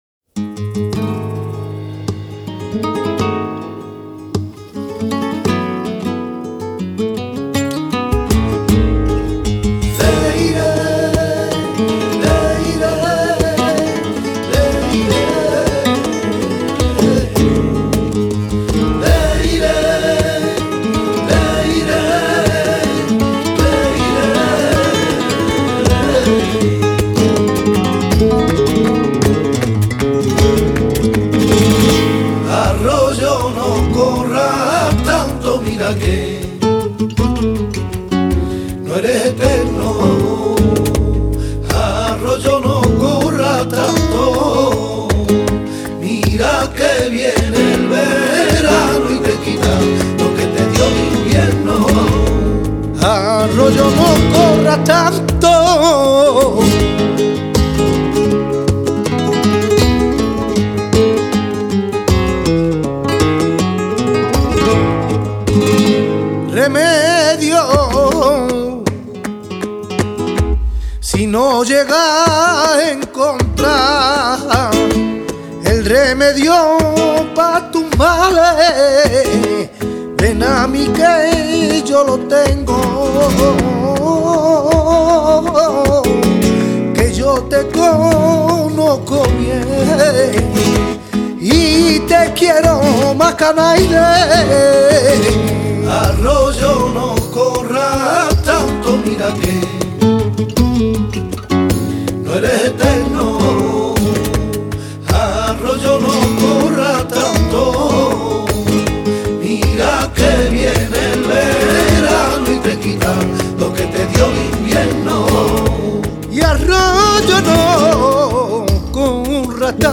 fandangos de Huelva
chant
guitare
chœurs
percussions
palmas